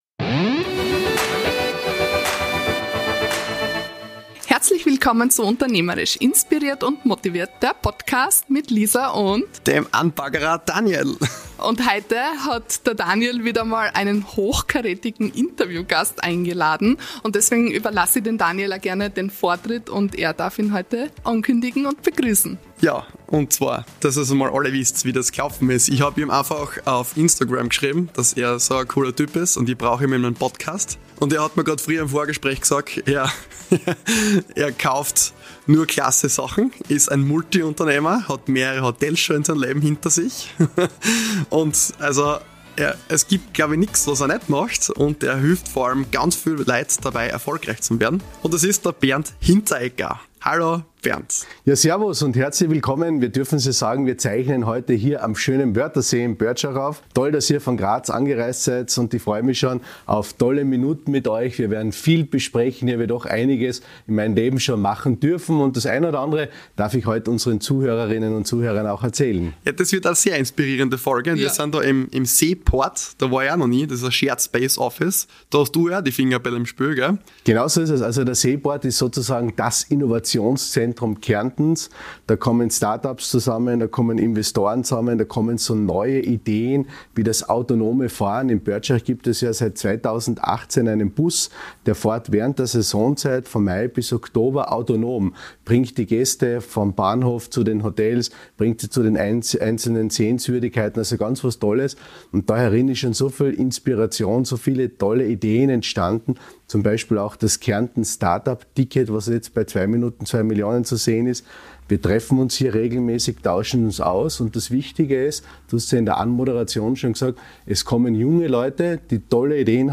Episode 24 - Visionen und Unternehmergeist (Interview